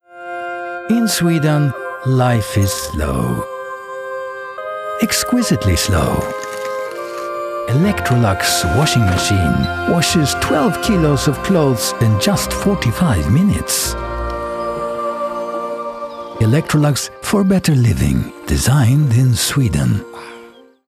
Swedish - Male
Commercial, Cool, Smooth, Confident